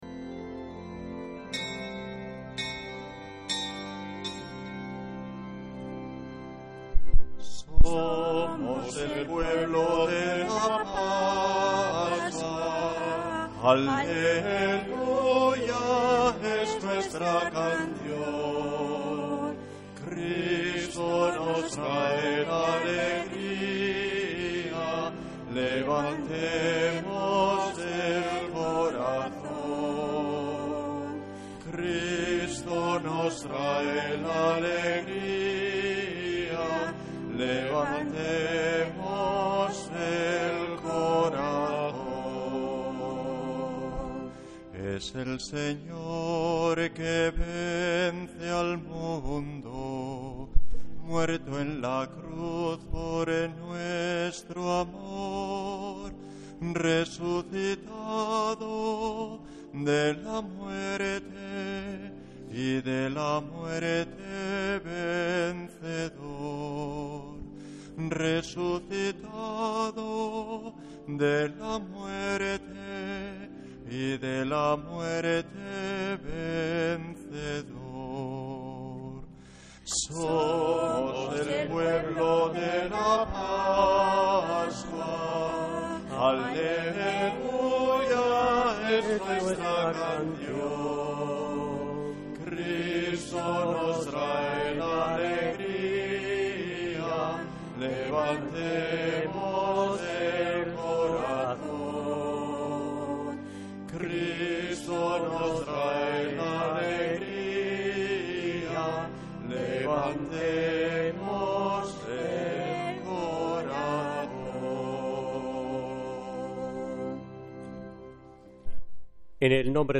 Santa Misa desde San Felicísimo en Deusto, domingo 26 de abril de 2026